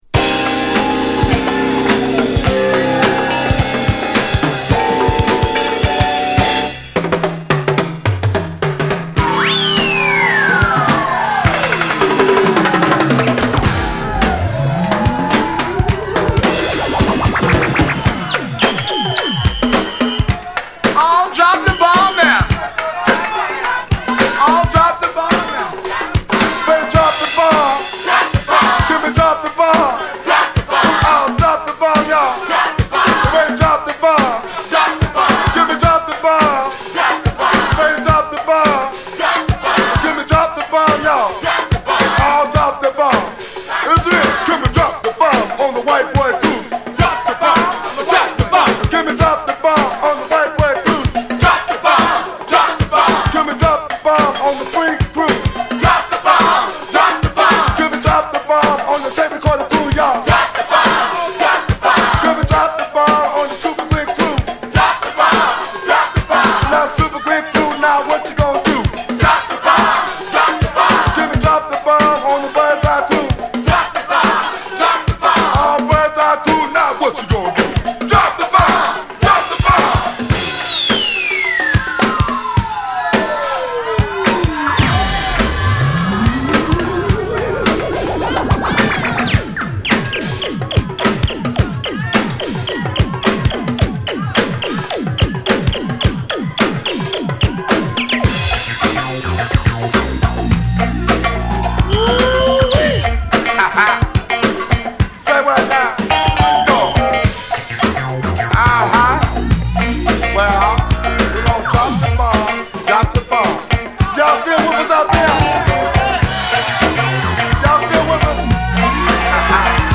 チャカポコしたリズムにシンセが絡むトラックにパーティー感あるヴォーカル。